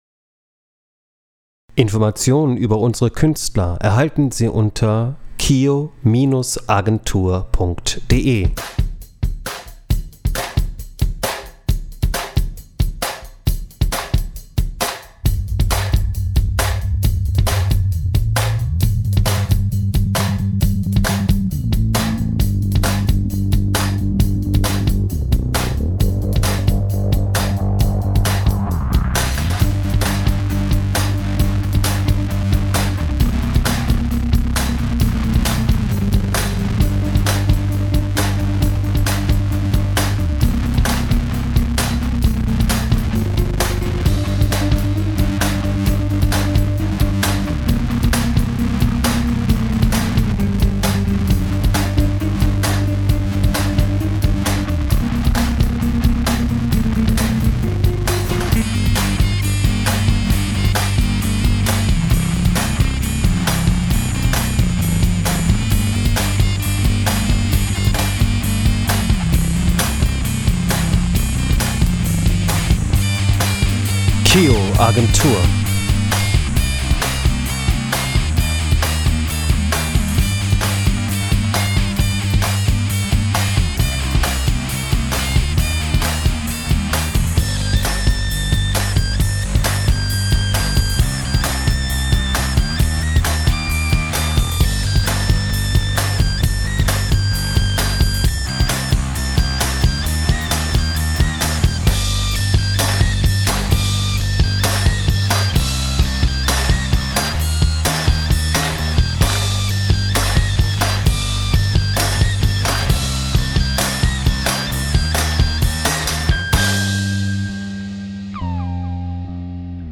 [Instrumental]